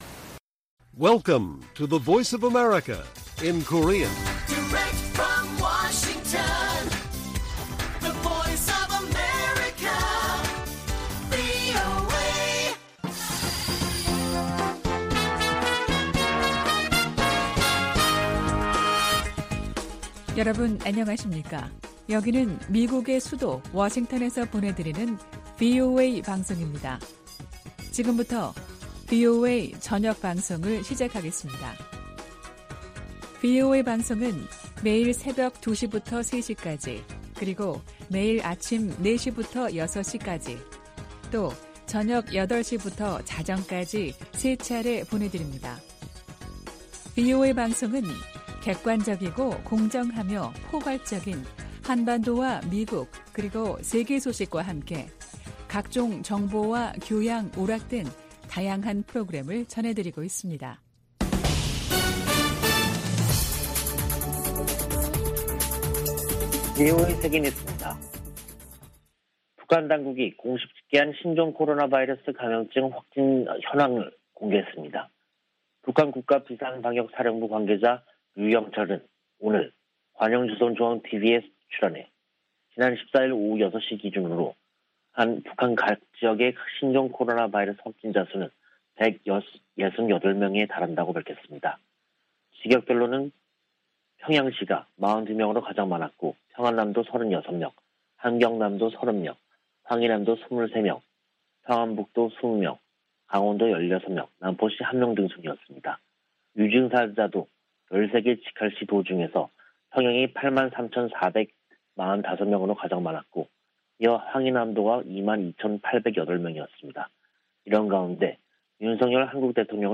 VOA 한국어 간판 뉴스 프로그램 '뉴스 투데이', 2022년 5월 16일 1부 방송입니다. 북한에서 신종 코로나바이러스 감염증이 폭증한 가운데, 윤석열 한국 대통령은 백신 등 방역 지원을 아끼지 않겠다고 밝혔습니다. 미 국무부는 한국 정부의 대북 백신 지원 방침에 지지 입장을 밝히고, 북한이 국제사회와 협력해 긴급 접종을 실시할 것을 촉구했습니다. 미국과 아세안은 특별정상회의에서 채택한 공동 비전성명에서 한반도의 완전한 비핵화 목표를 확인했습니다.